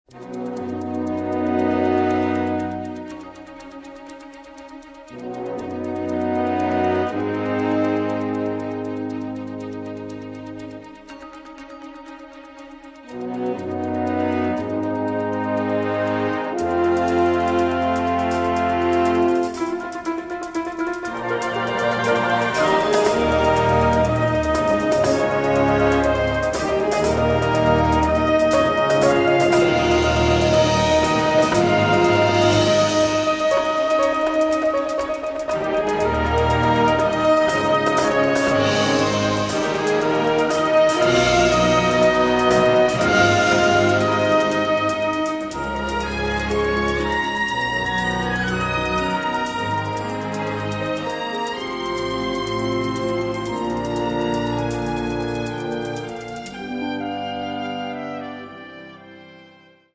Slack Key Guitars